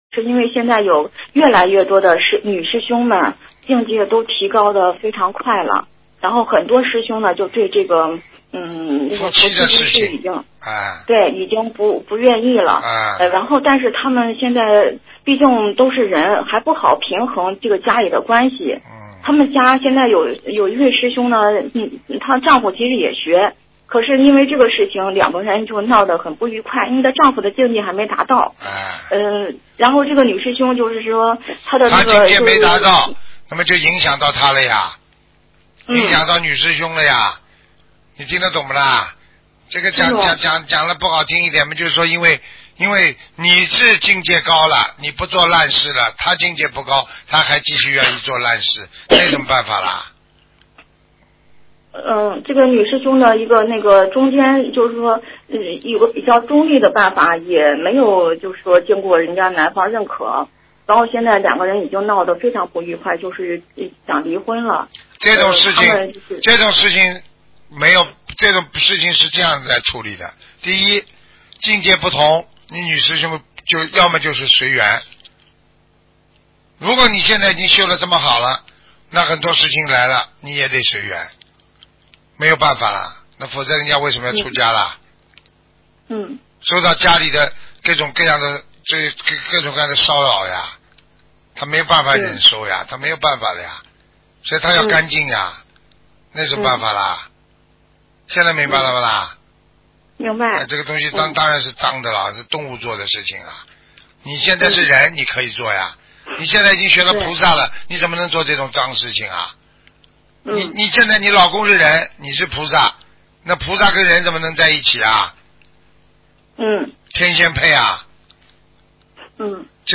女听众：我前一段时间，晚上上香之前问菩萨我修得怎么样，晚上梦见四五页纸上面写的就是那种跟天书一样，我一点都看不懂。